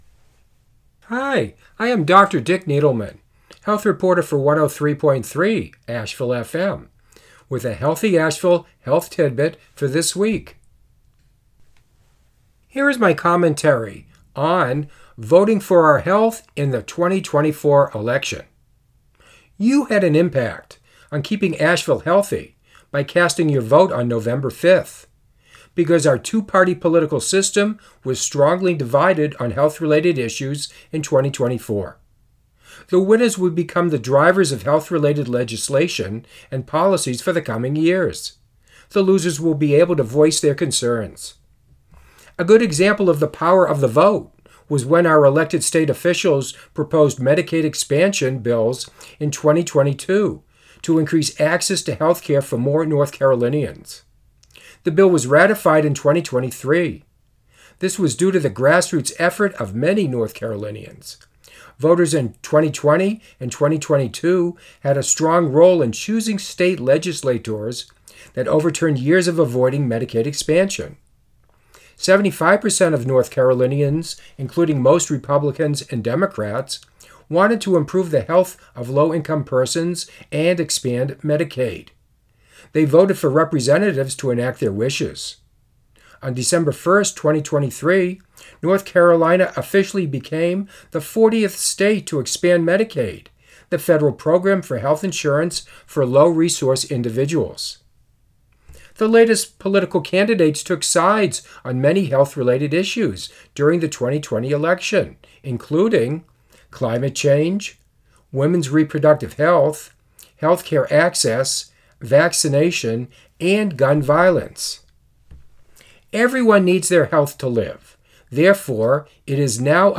Voting for our health in the 2024 election: A commentary